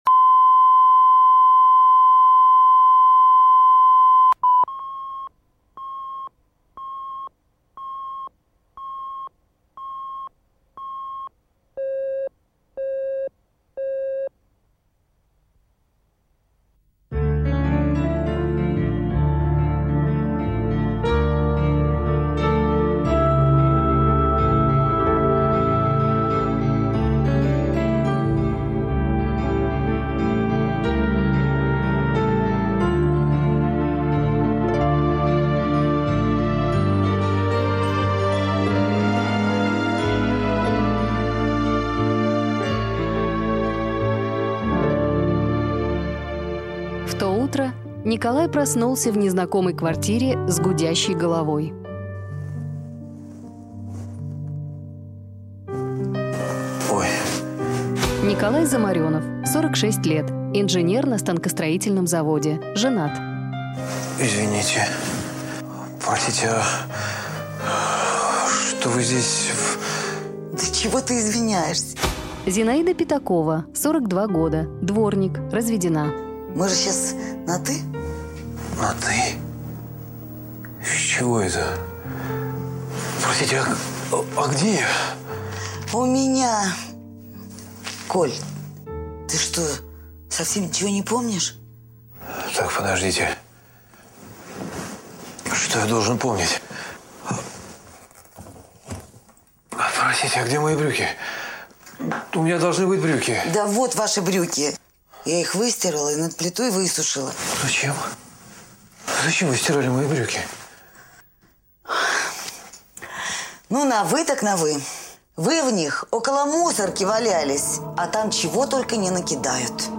Аудиокнига Дворовый роман